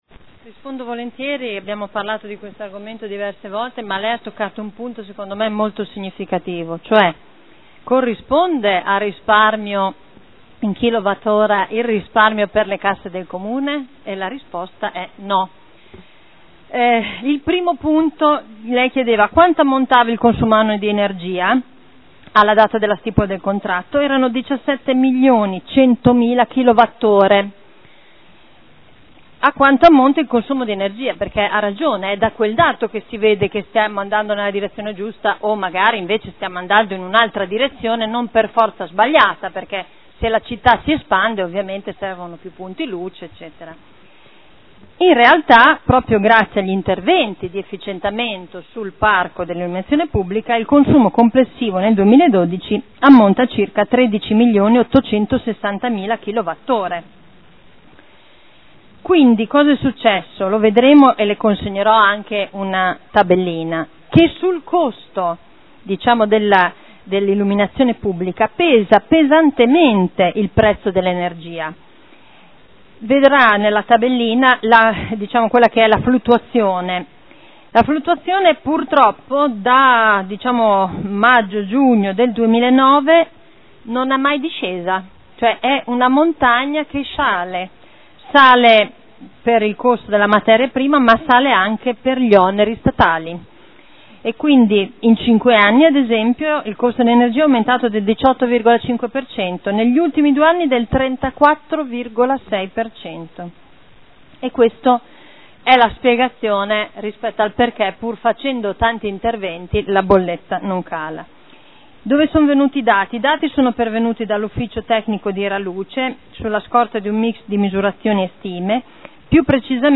Simona Arletti — Sito Audio Consiglio Comunale